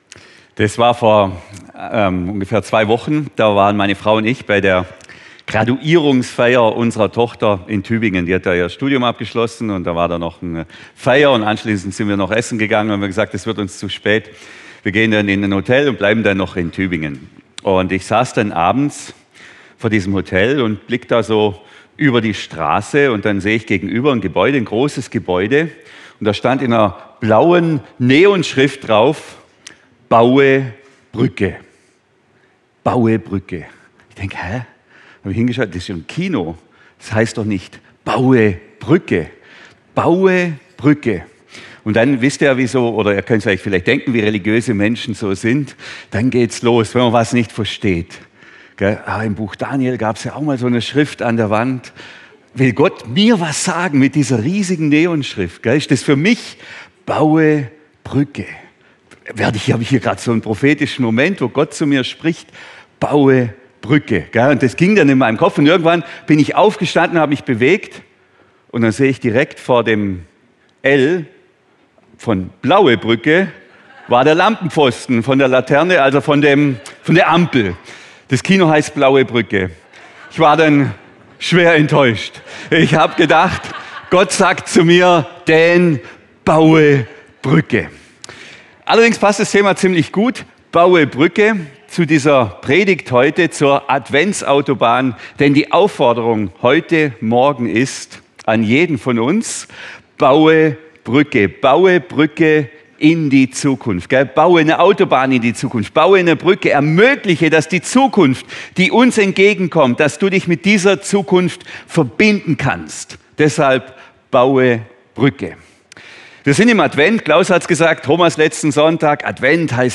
Die Adventsautobahn – Freie Fahrt für den König! ~ LIWI-Predigten Podcast